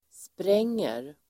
Uttal: [spr'eng:er]